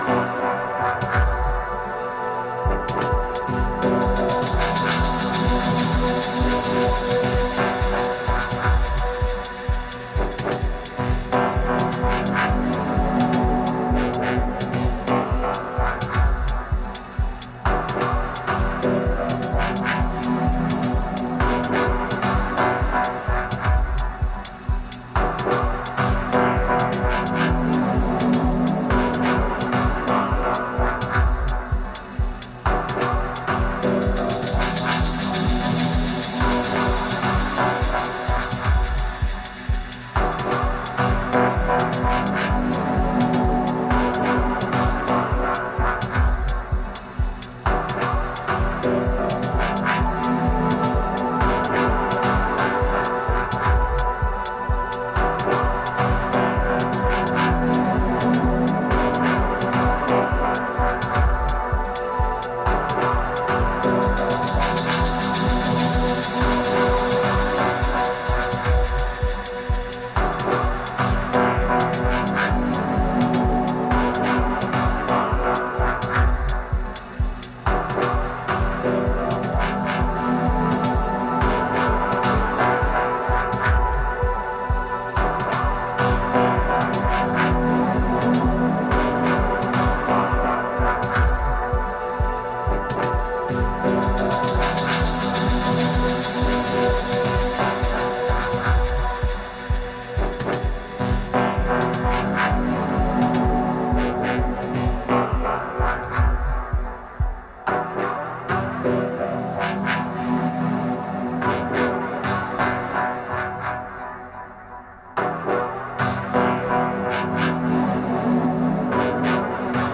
Dub Techno Techno